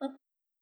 pegchamp/SFX/boop/D#.wav at alpha
better sound effects
D#.wav